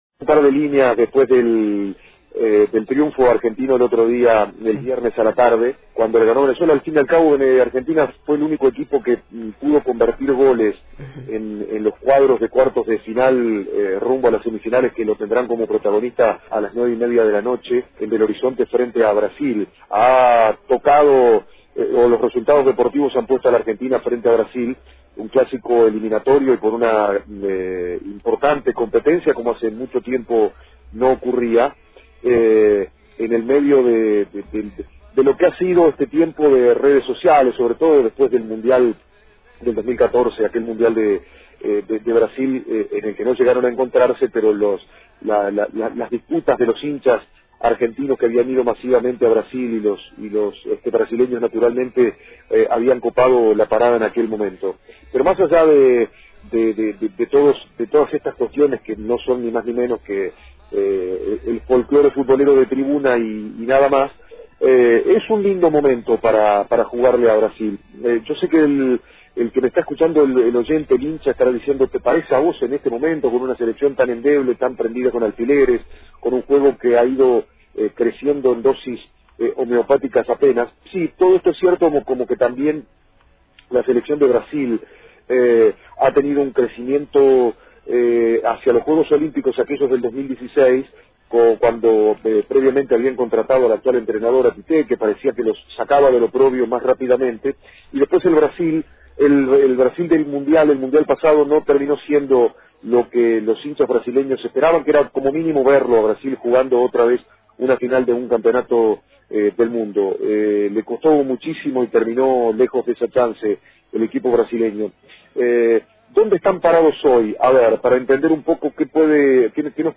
El comentario, en la previa